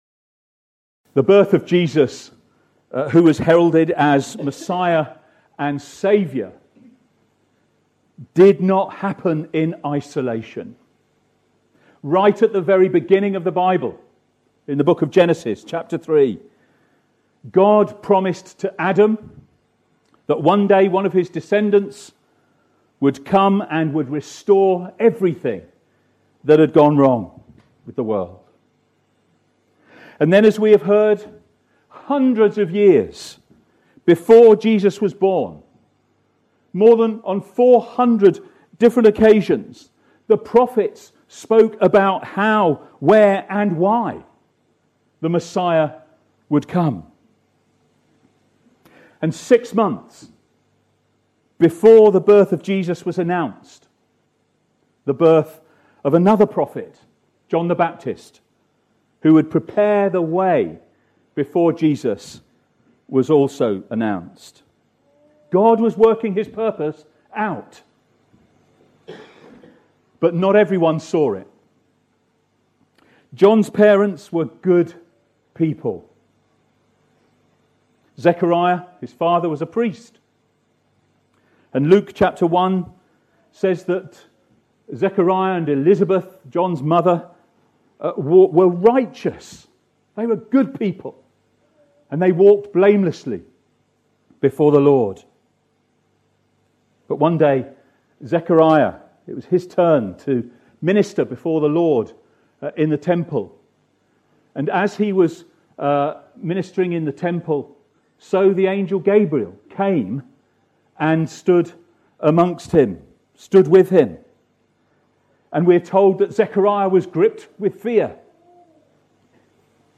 At our carol service